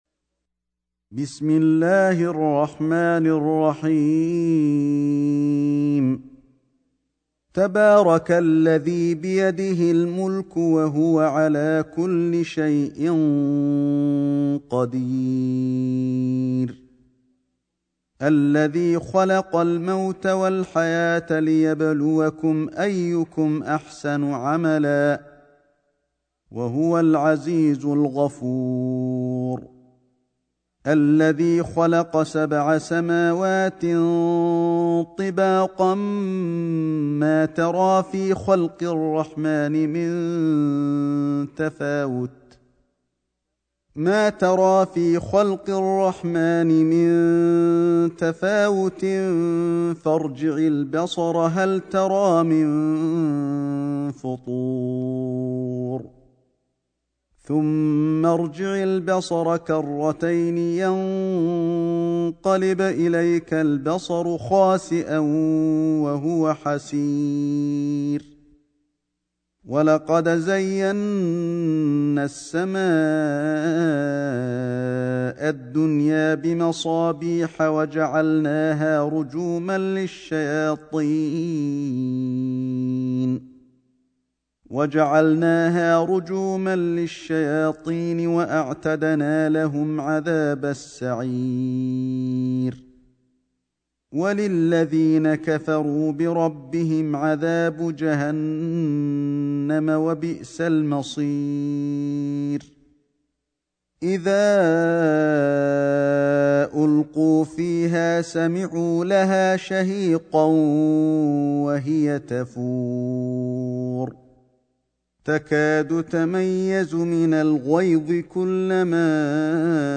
سورة الملك > مصحف الشيخ علي الحذيفي ( رواية شعبة عن عاصم ) > المصحف - تلاوات الحرمين